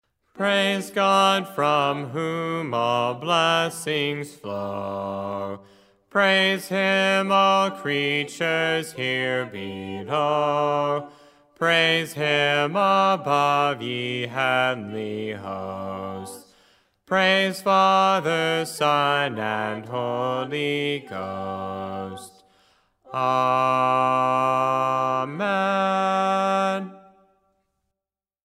Gospel Church, here are the audio files for the different parts of the Doxology to be practicing.
50-Doxology-Bass.mp3